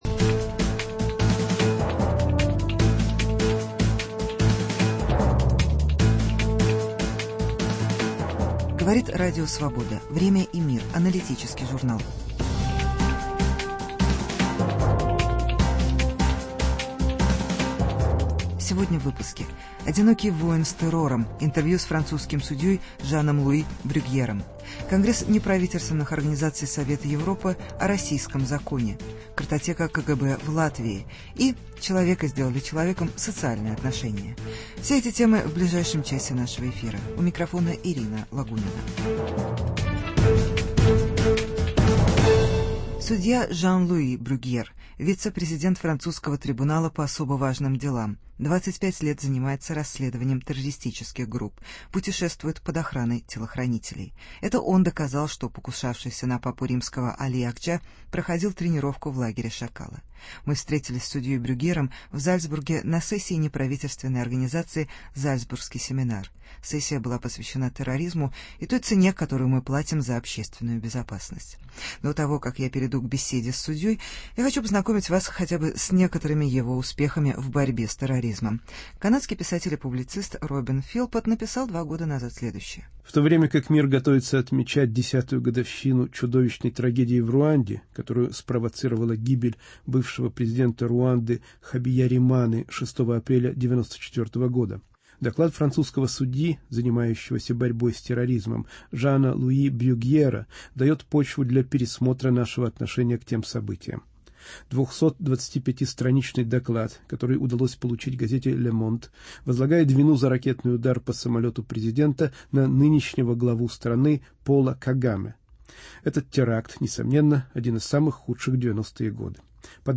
Интервью с судьей Жаном-Луи Брюгьером (Jean-Louis Bruguiere).